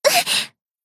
贡献 ） 分类:蔚蓝档案语音 协议:Copyright 您不可以覆盖此文件。
BA_V_Tomoe_Battle_Damage_2.ogg